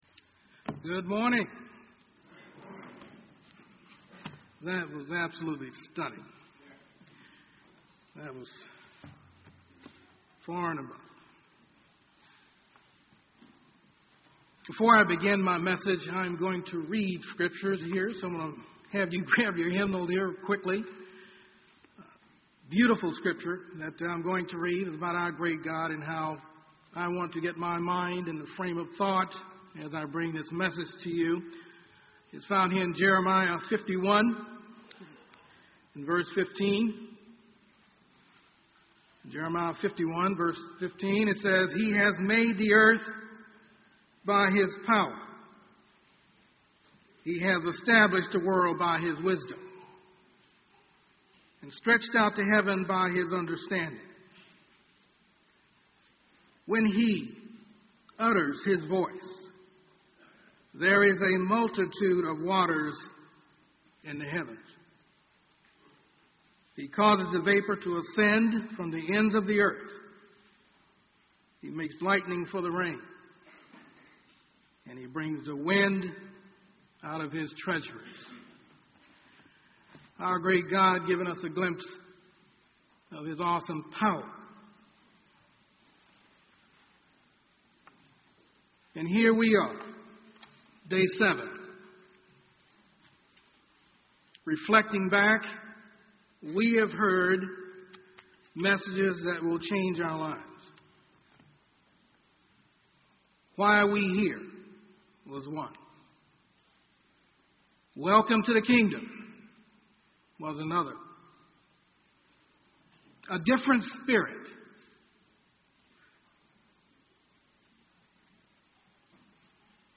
This sermon was given at the Phoenix, Arizona 2016 Feast site.